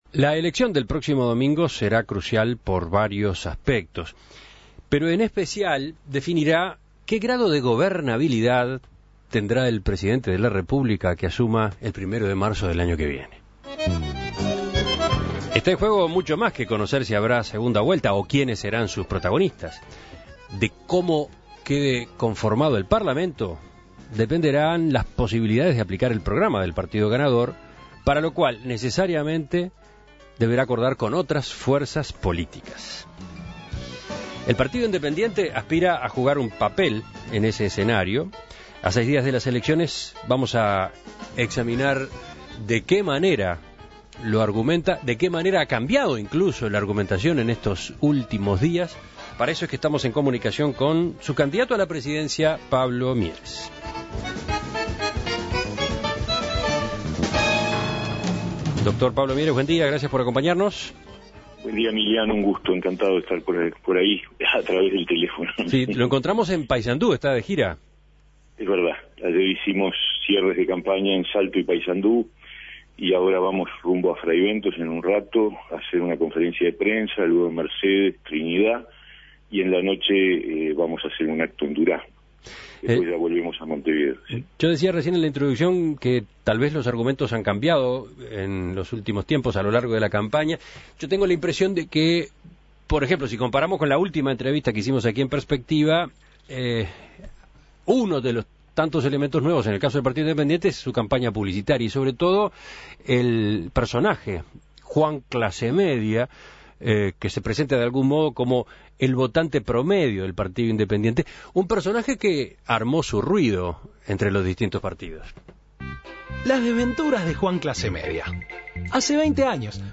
En la semana final, En Perspectiva entrevistó al candidato por el PI.